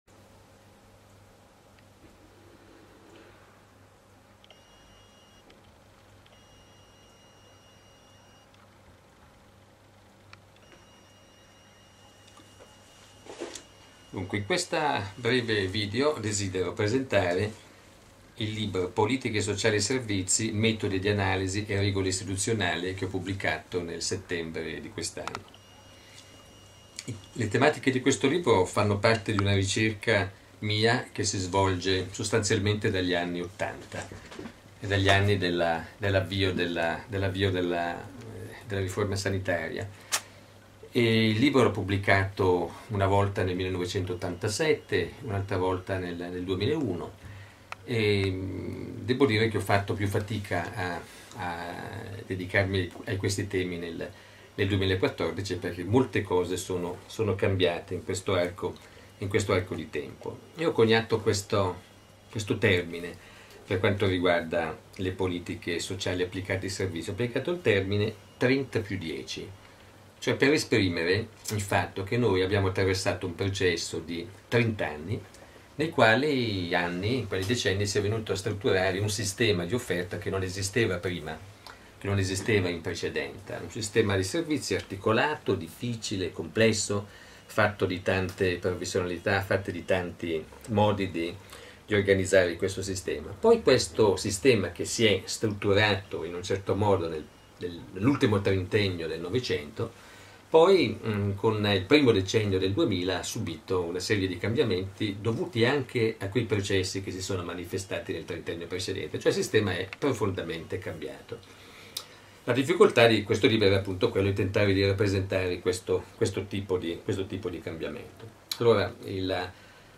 • qui la presentazione del libro in formato AUDIO: